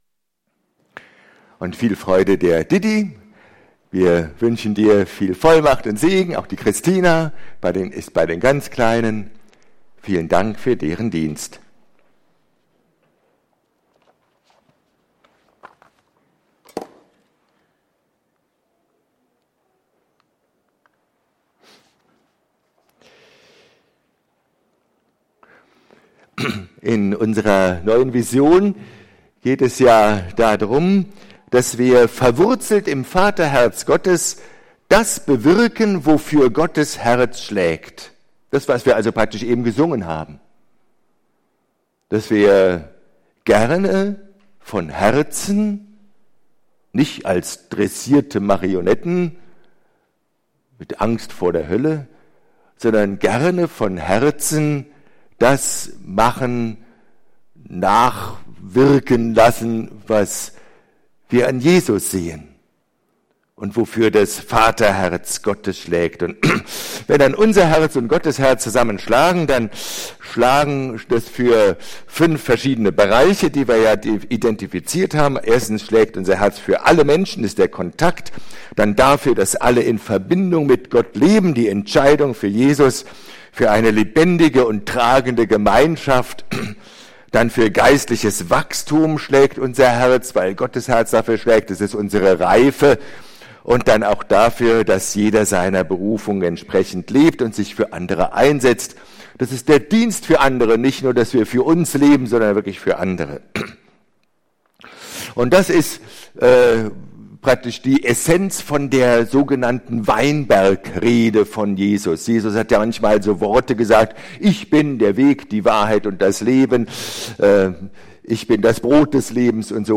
Predigt vom 13.12.2020